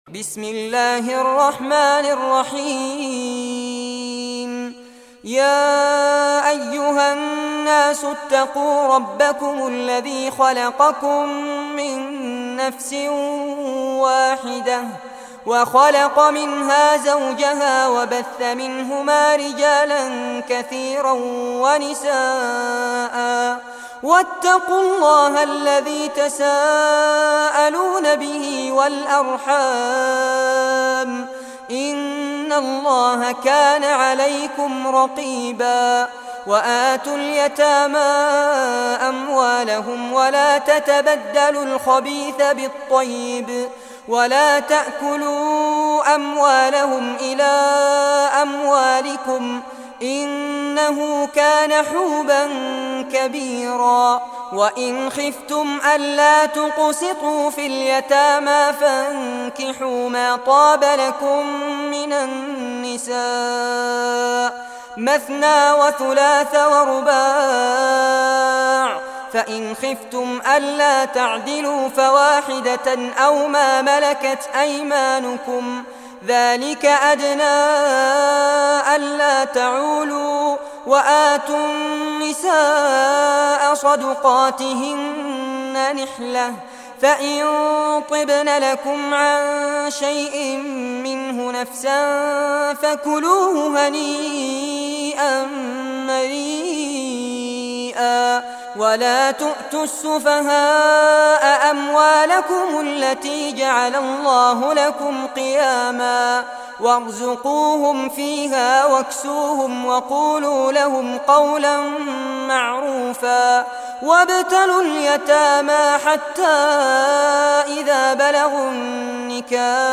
Surah Nisa Recitation by Sheikh Fares Abbad
Surah Nisa, listen or play online mp3 tilawat / recitation in Arabic in the voice of Sheikh Fares Abbad.
4-surah-nisa.mp3